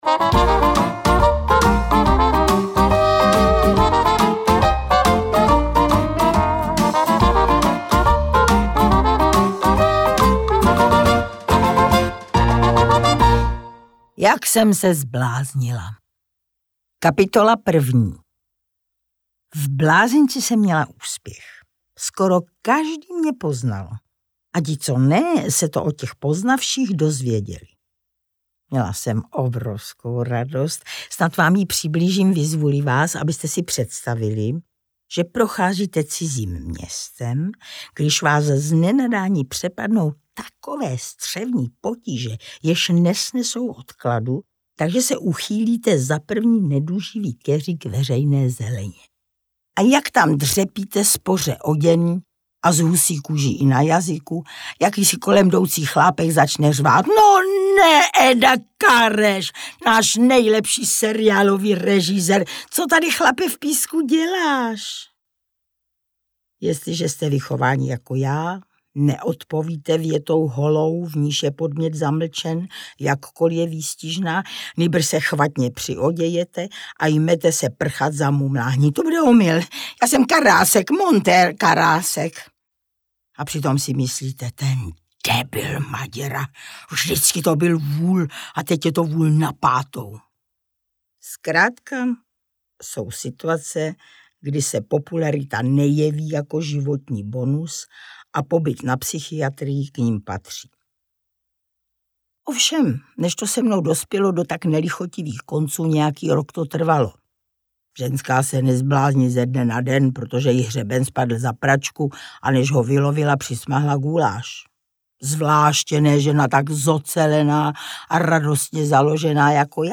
Interpret:  Ivanka Devátá
Kniha Ivanky Deváté tentokrát k poslechu v interpretaci samotné autorky! Pracovní zatížení, hluční sousedé a další sled událostí ji totiž dovedli až do blázince.